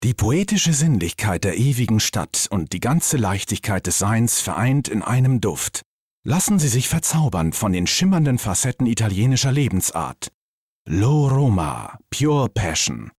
dunkel, sonor, souverän
Mittel plus (35-65)
Commercial (Werbung)